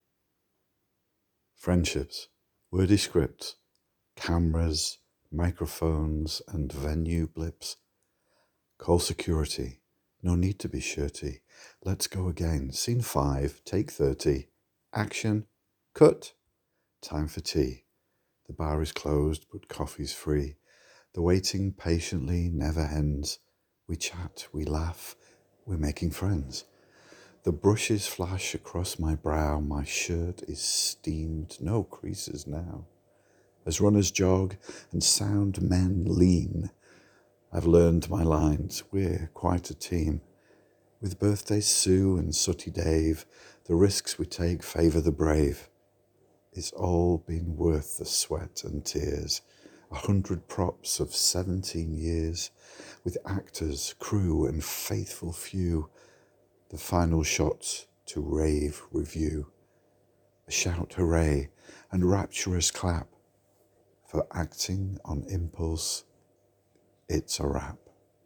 You have a soothing voice.